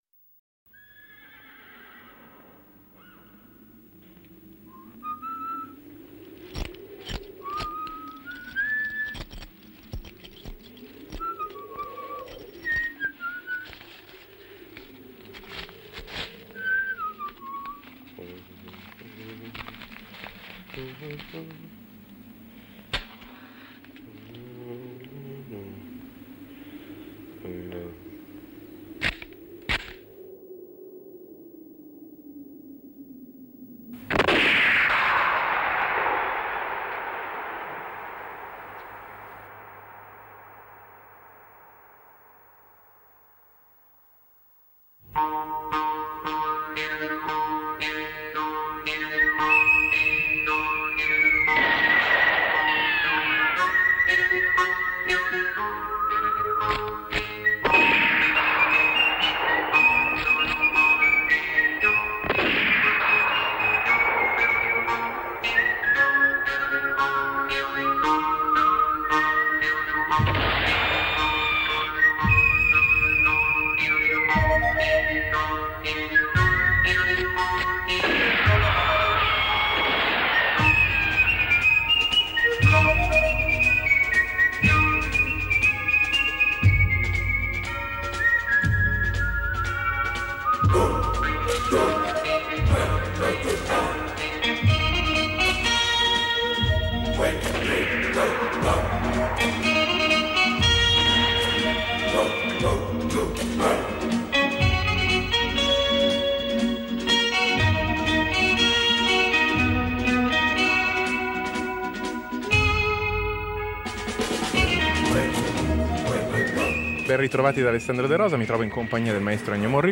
È difficile fermare in una sola intervista un simile personaggio e le tante identità che lo abitano.